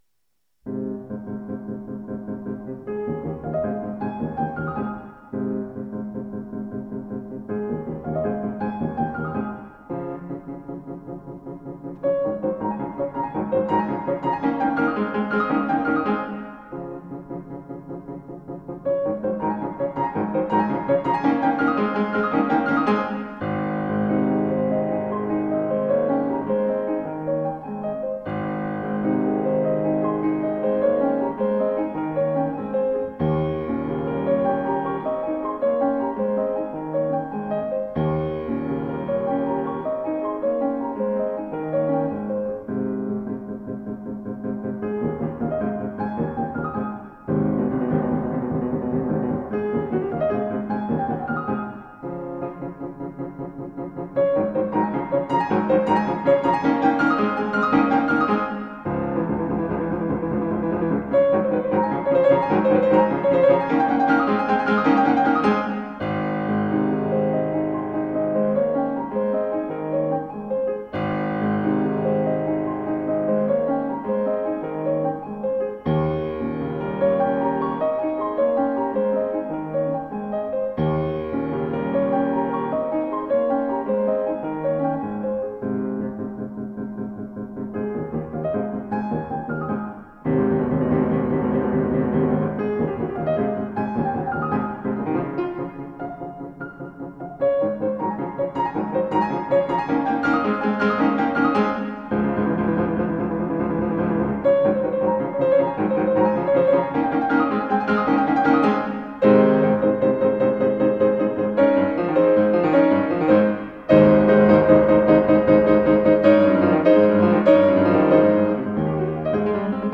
Classical works from a world renowned pianist.
A bagatelle is a kind of brief character piece.